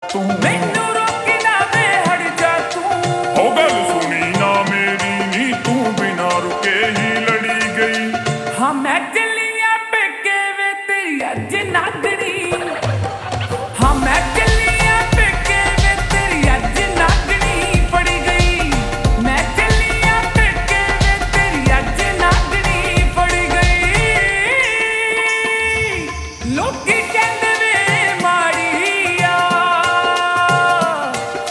Punjabi Songs
(Slowed + Reverb)